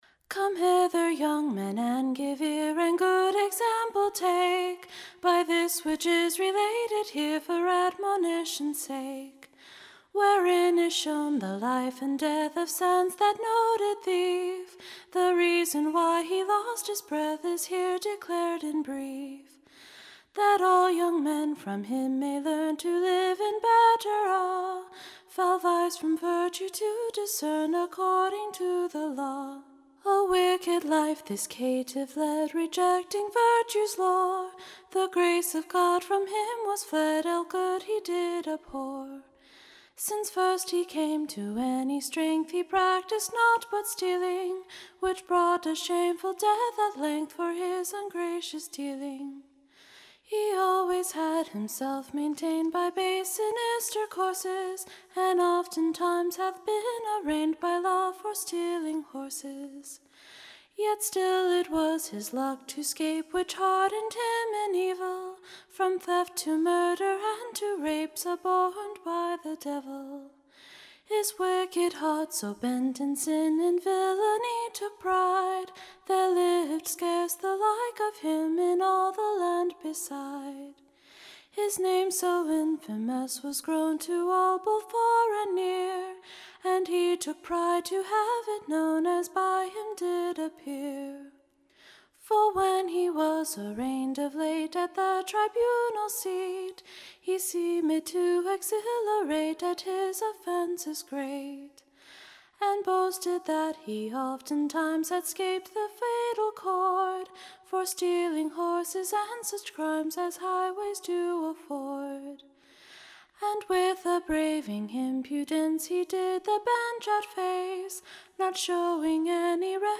EBBA 20055 - UCSB English Broadside Ballad Archive